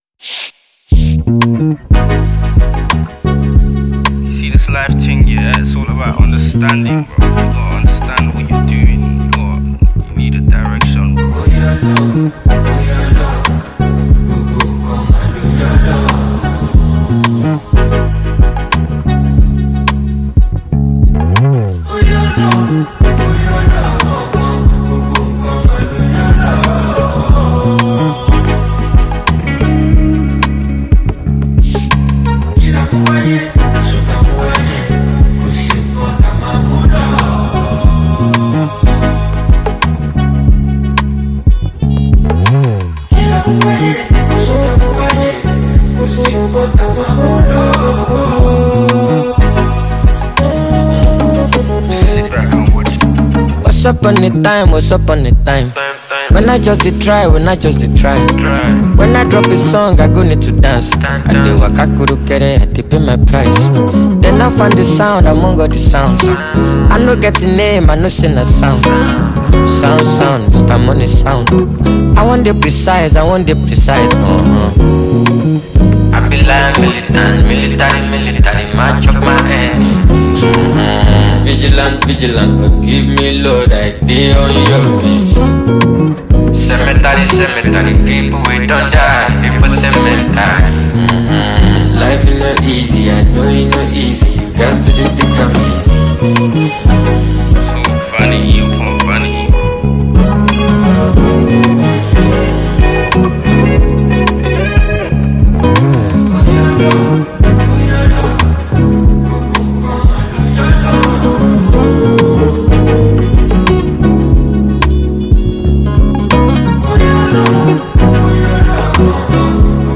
catchy melodies and heartfelt lyrics